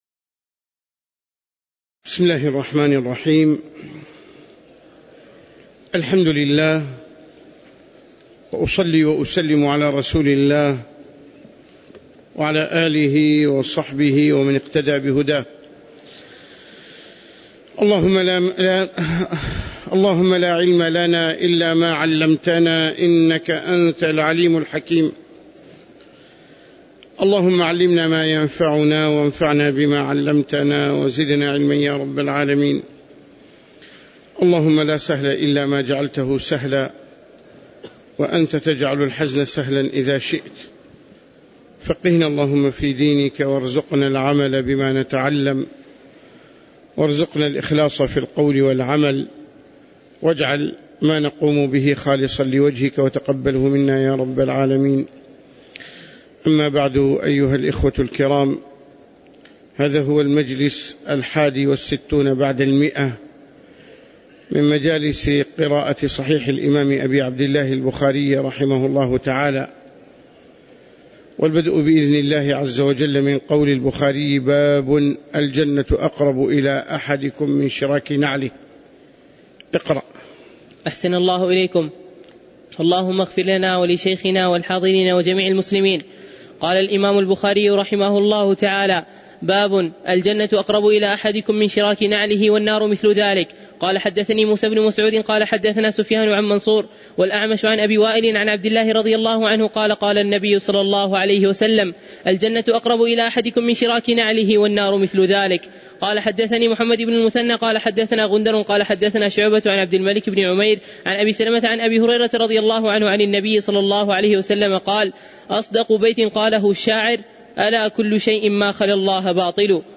تاريخ النشر ٥ صفر ١٤٣٩ هـ المكان: المسجد النبوي الشيخ